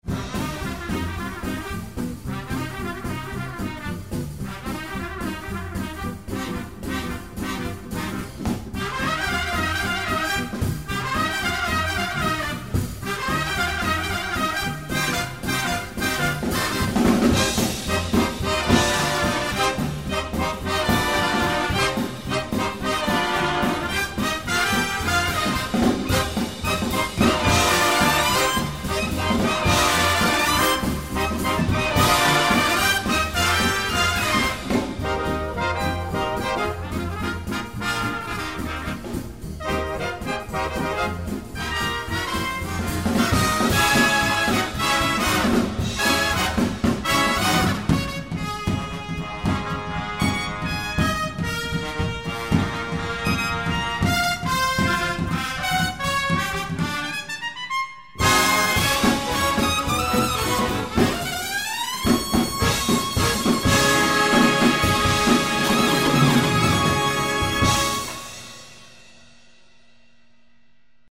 Arrangement for trumpet ensemble